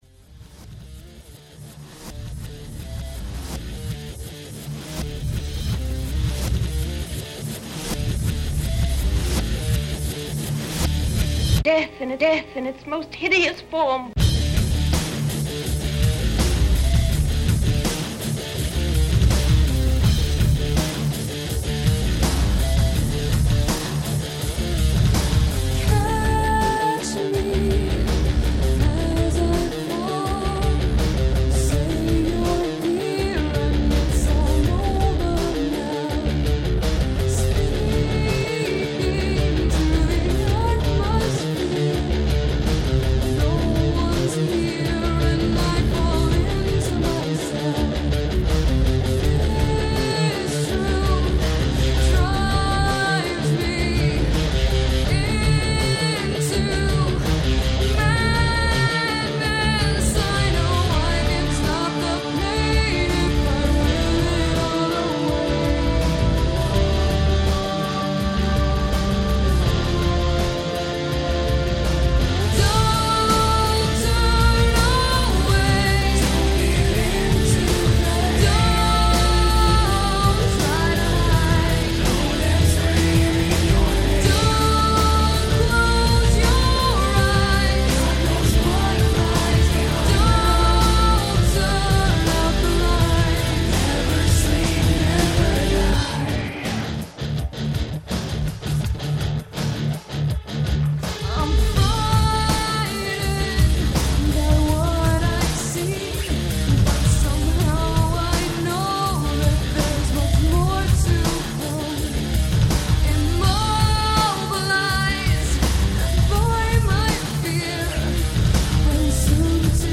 Жанр: Gothic Metal